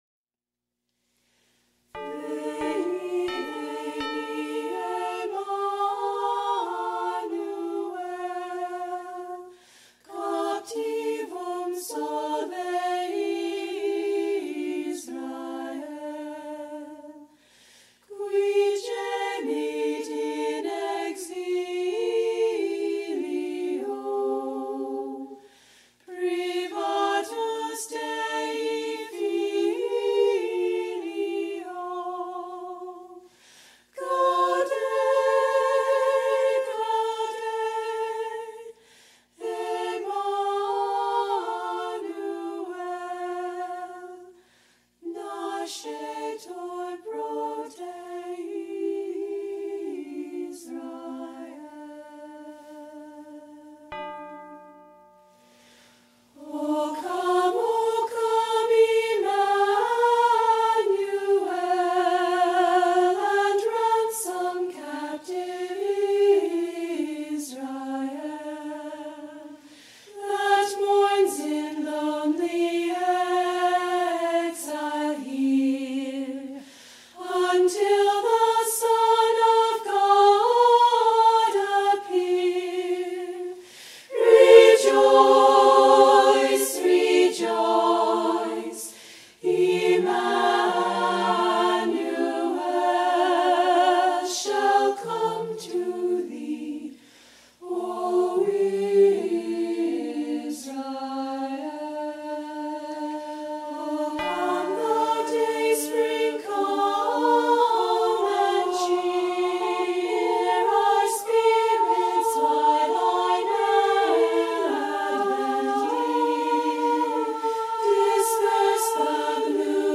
Chant notation for the beloved Advent hymn.
*Courtesy of the CMRI Sisters*